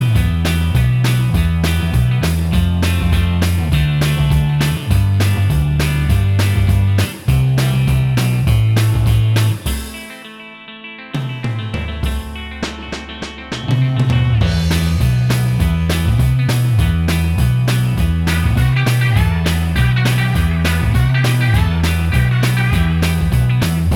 Minus Lead Acoustic Rock 2:49 Buy £1.50